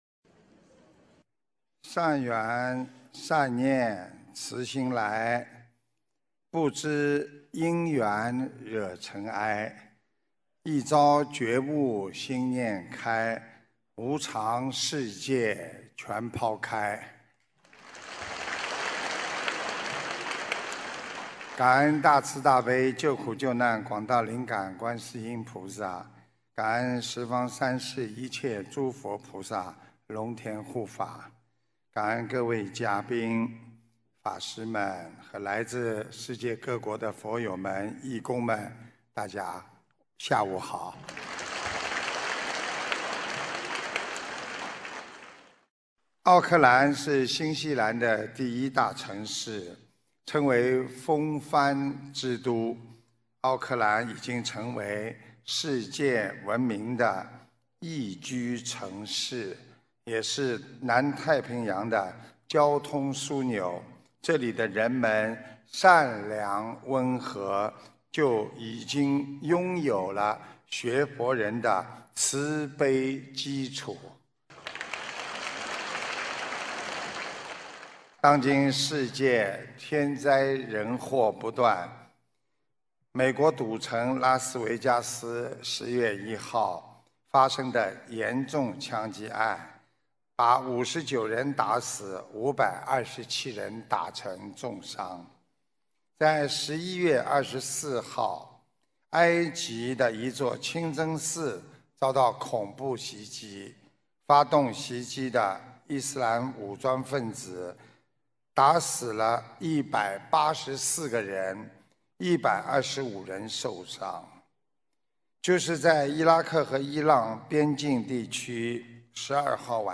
2017年12月3日新西兰奥克兰解答会开示（视音文） - 2017年 - 心如菩提 - Powered by Discuz!